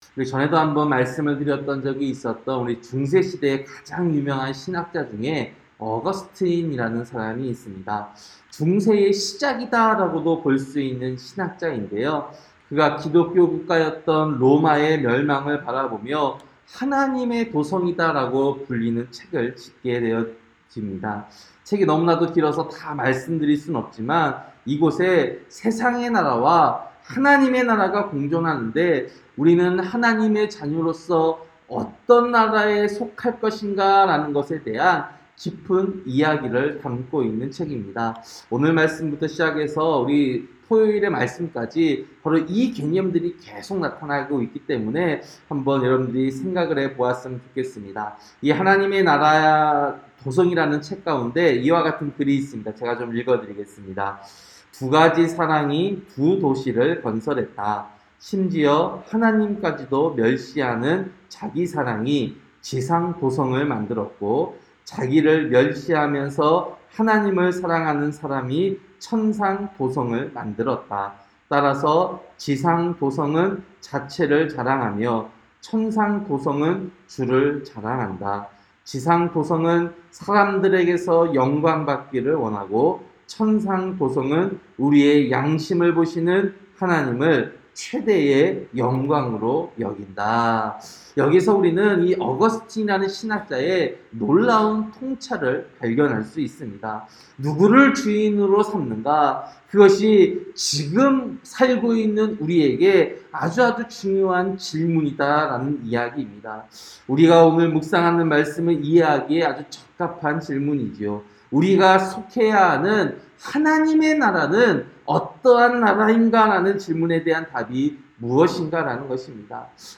새벽설교-사무엘하 2장